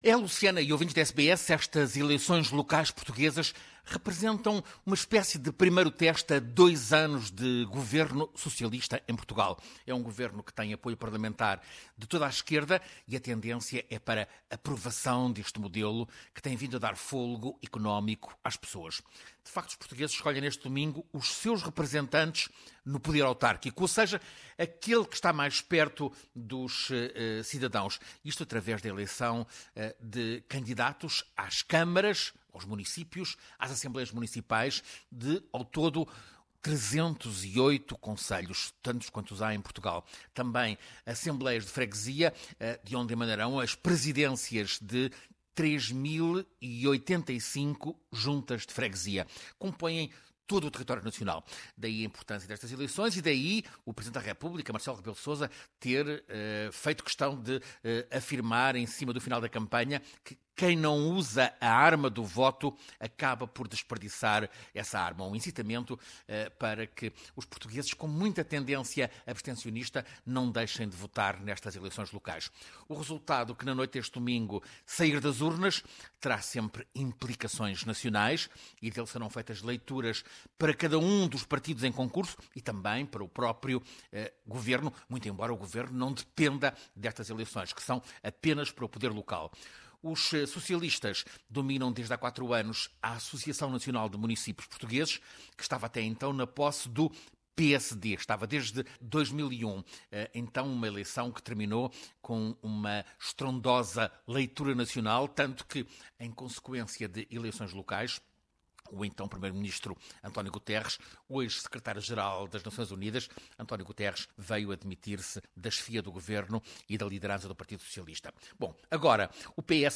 As eleições municipais portuguesas, que acontecem neste 1º de outubro são um teste, com provável aprovação, do governo socialista. O PSD, do ex-primeiro-ministro Passos Coelho, está ameaçado, nas pesquisas, de resultado desastroso nas principais cidades. Ouça reportagem